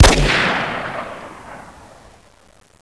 vote_sound.wav